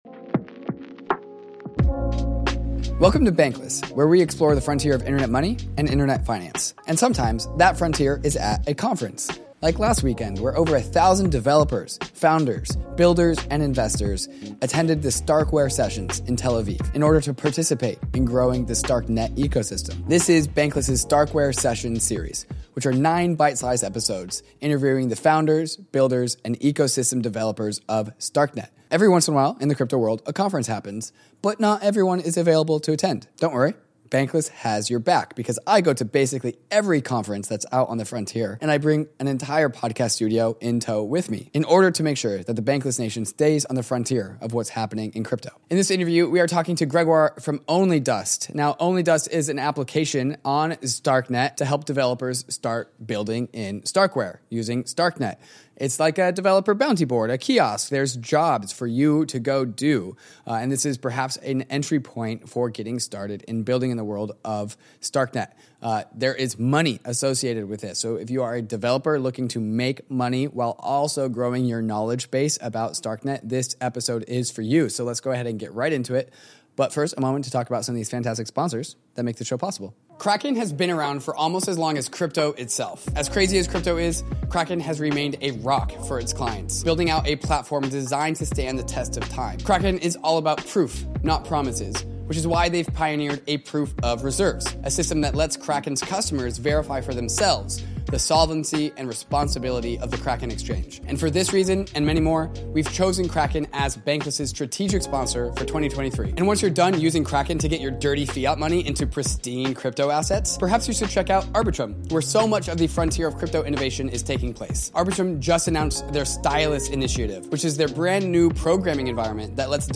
Sometimes, the frontier is at a crypto conference.